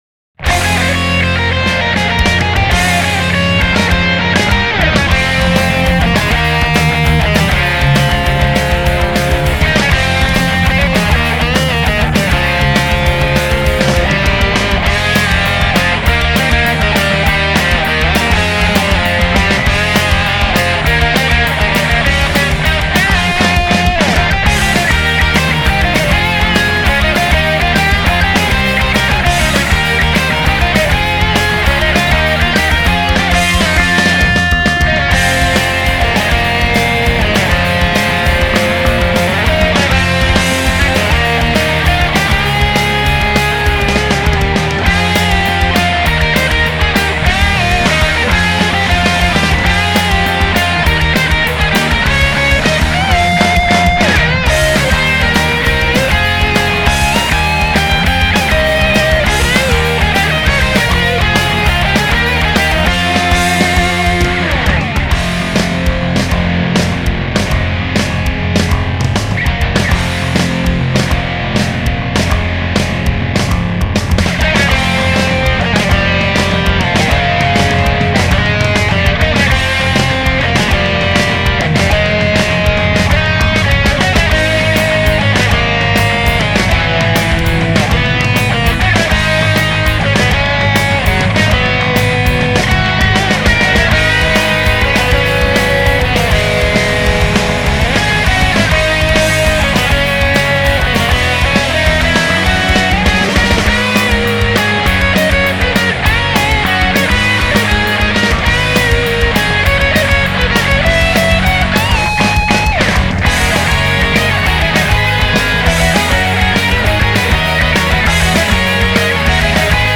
This remix is for you